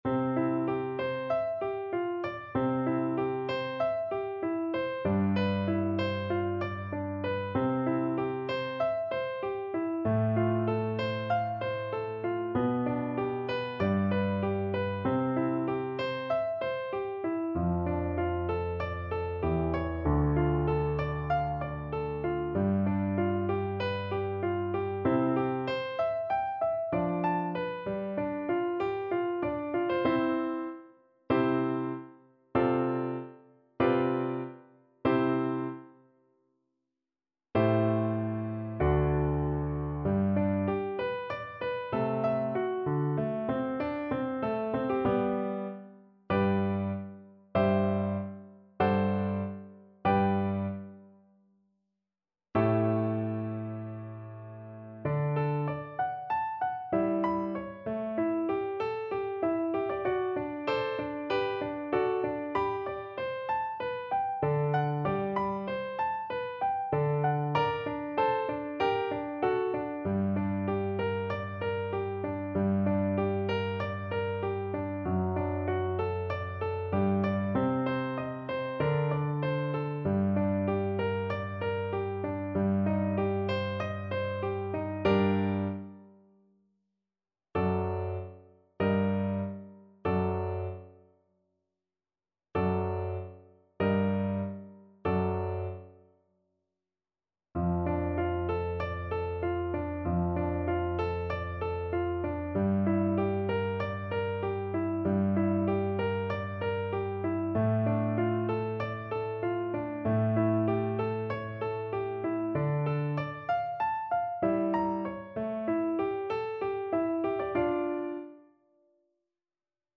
~ = 96 Andante
Classical (View more Classical Flute Music)